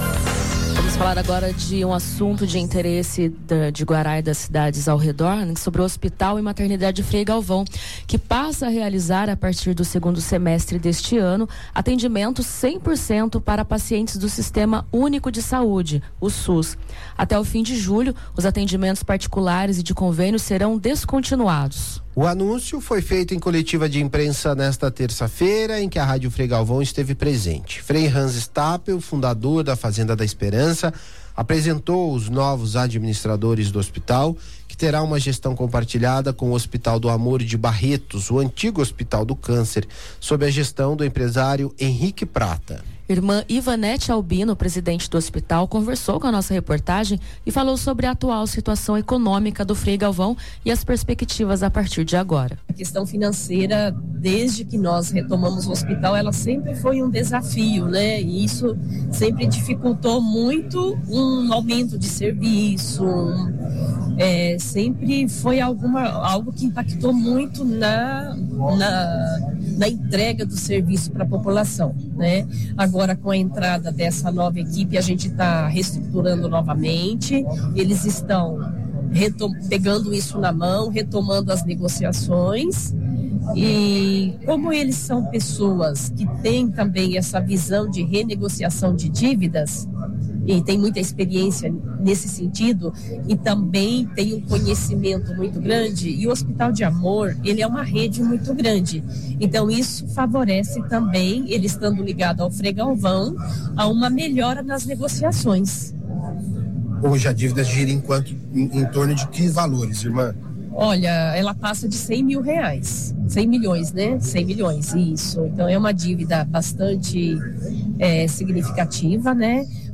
⏯Ouça a reportagem completa veiculada no Jornal da Manhã Regional desta quarta-feira (28/05):